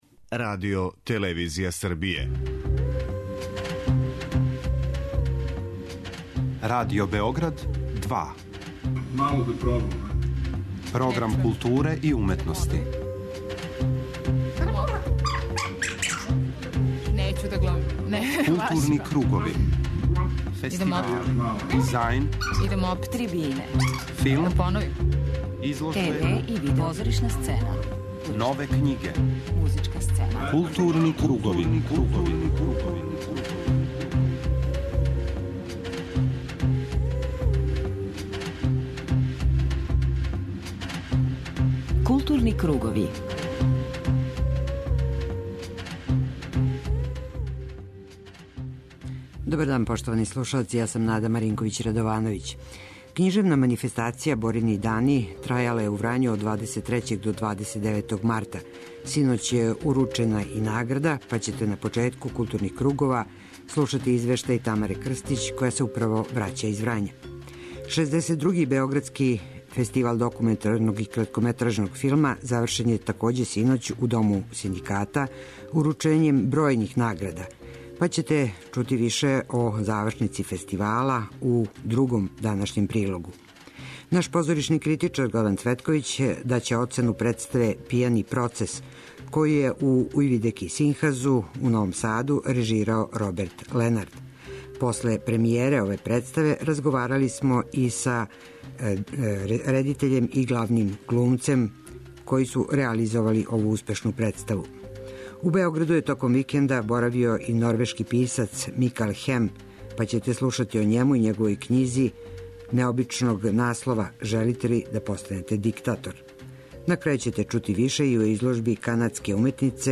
преузми : 52.23 MB Културни кругови Autor: Група аутора Централна културно-уметничка емисија Радио Београда 2.